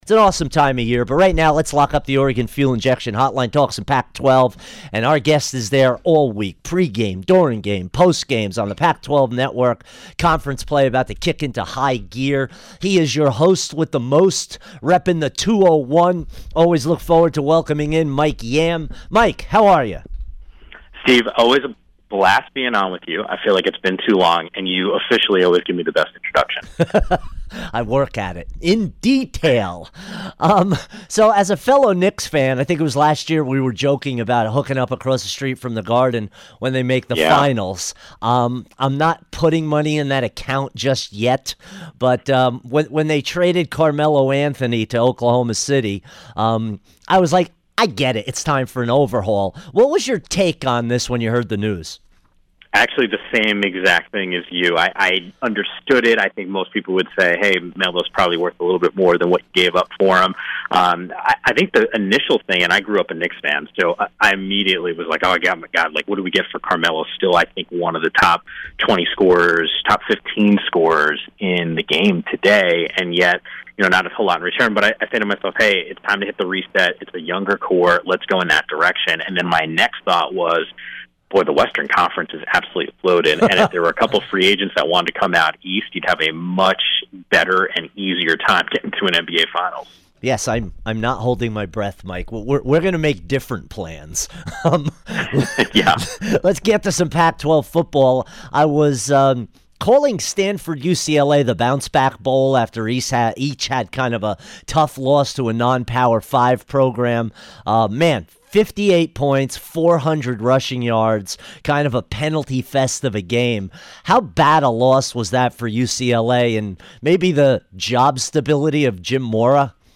Mike Yam Interview 9-25-17